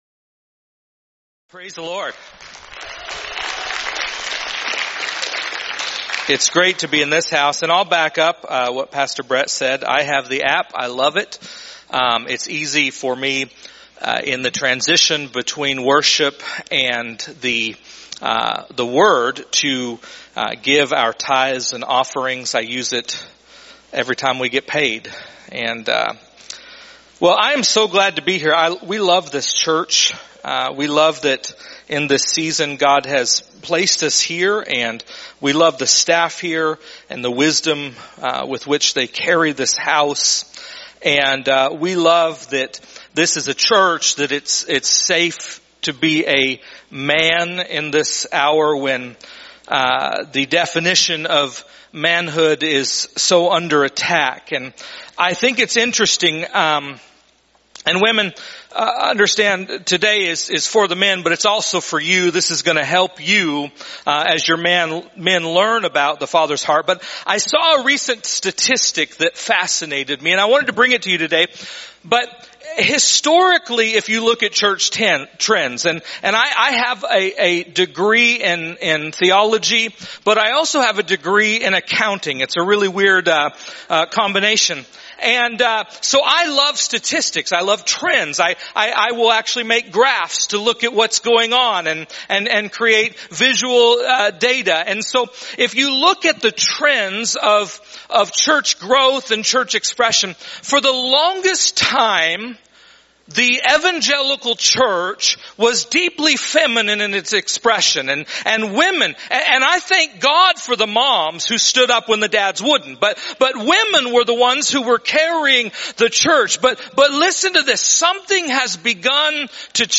Sermons | Covenant Christian Church